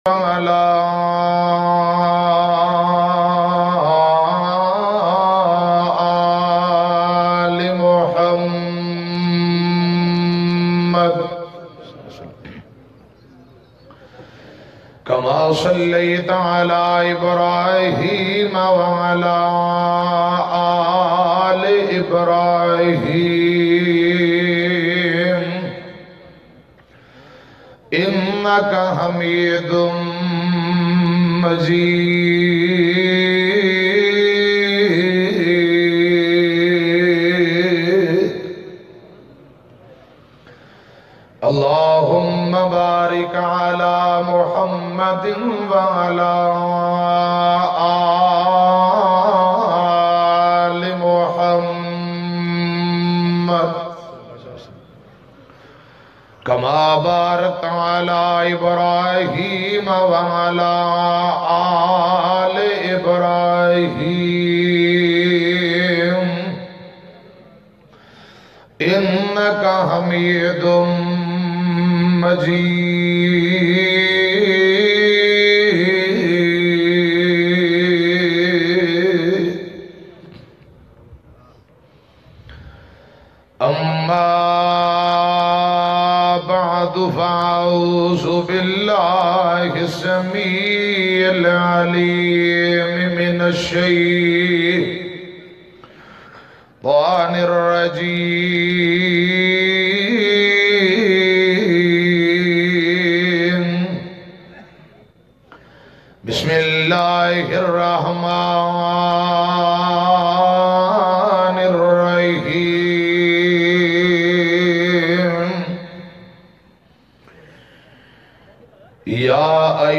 Moat Aisi Jo Jannat Le Jae bayan mp3